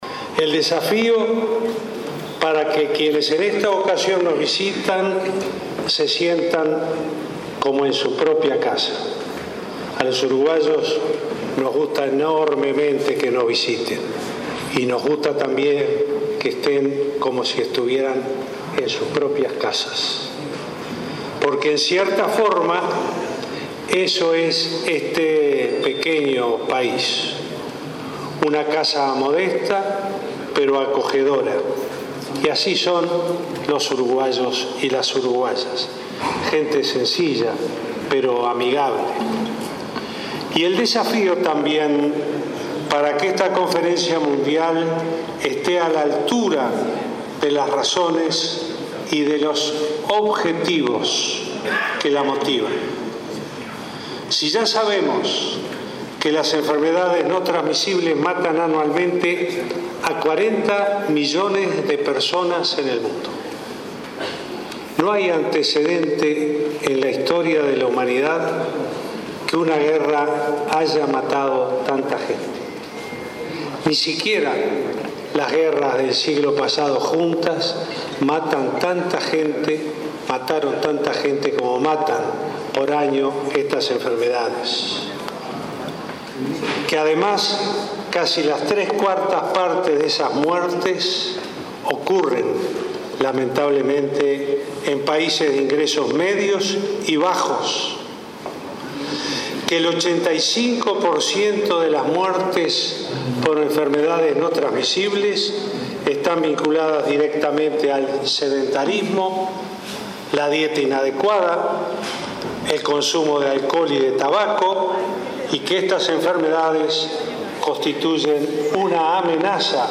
El presidente de Uruguay, Tabaré Vázquez, advirtió sobre la dimensión del problema de las enfermedades no transmisibles, que matan en el mundo a 40 millones de personas por año. Durante la apertura de la Conferencia Mundial sobre Enfermedades No Transmisibles, que se realiza en Montevideo entre el 18 y 20 de octubre, recordó que casi las tres cuartas partes de los fallecimientos ocurren en países de ingresos medios y bajos.